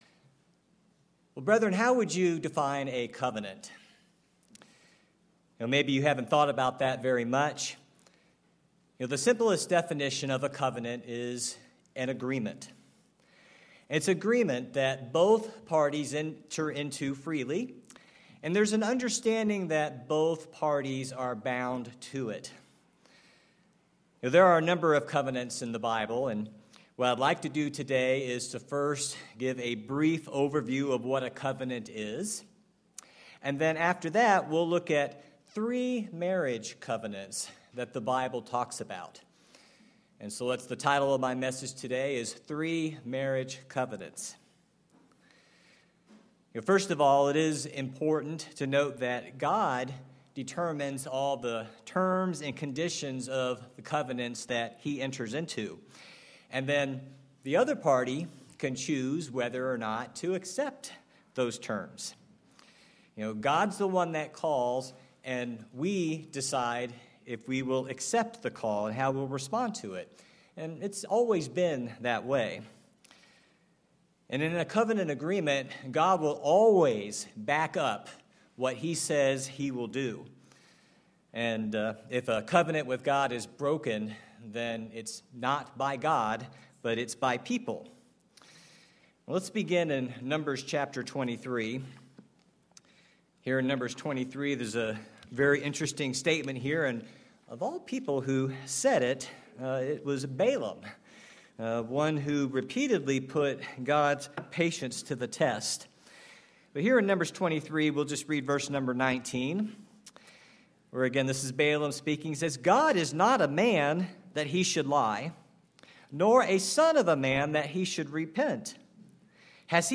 Sermons
Given in Kennewick, WA Chewelah, WA Spokane, WA